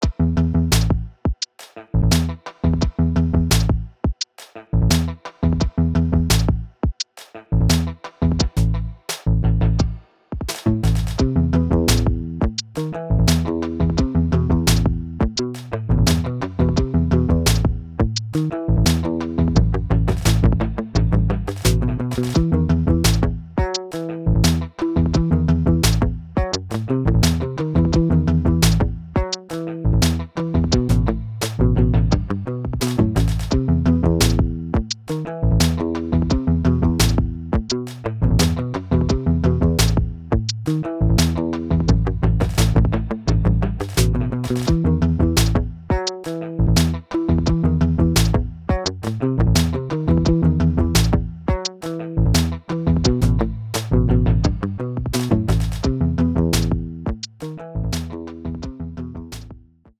This is a short jam by an anonymous enthusiastic Beatly user, which I received in my mailbox.
I first found the beat in Beatly, then loaded it into AB3 with Ableton Link enabled, and sent the output to AUM. In AUM, I added Riffler by exporting the riffle and pasting it into an instance of Neon, then looping it. Then, I recorded the mix, punching the guitar in after 4 bars.